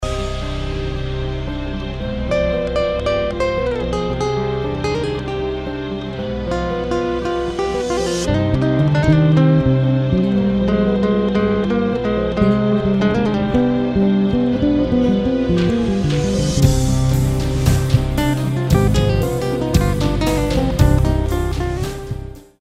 bass player